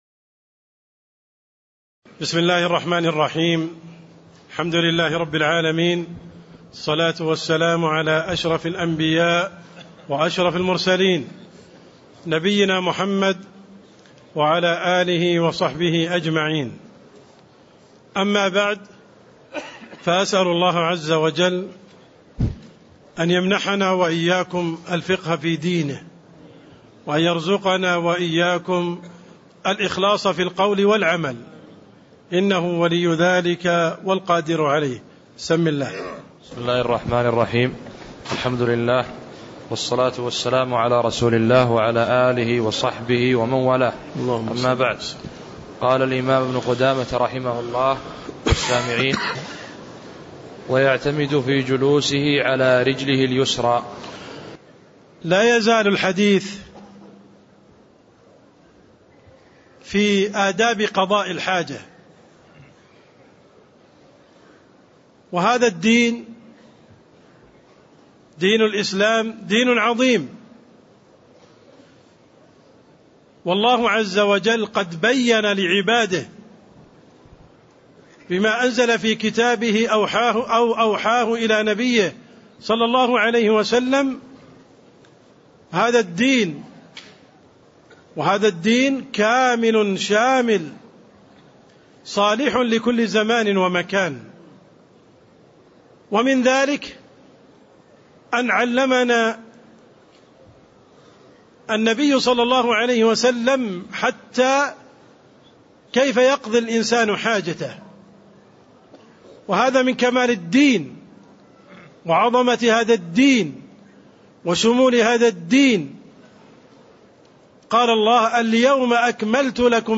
تاريخ النشر ١٧ جمادى الأولى ١٤٣٥ هـ المكان: المسجد النبوي الشيخ: عبدالرحمن السند عبدالرحمن السند اداب قضاء الحاجة من قوله: ويعتمد في جلوسه على رجله اليسرى (05)